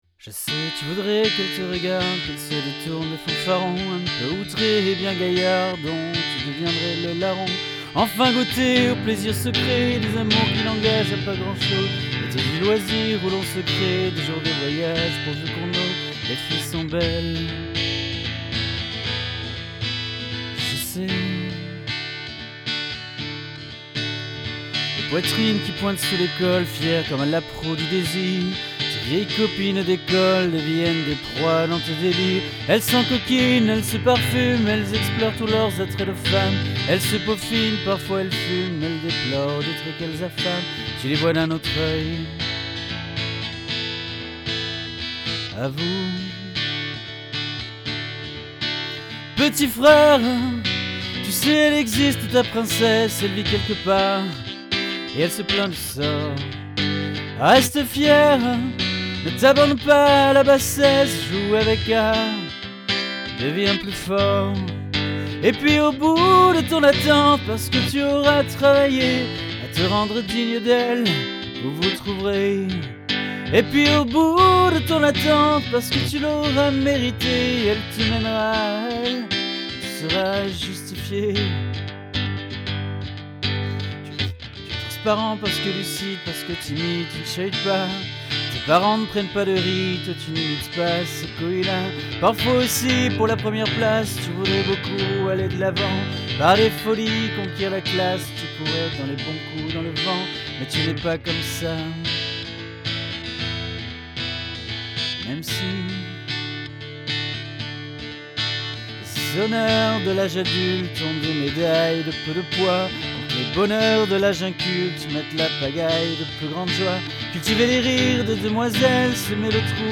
• Voix
• Guitare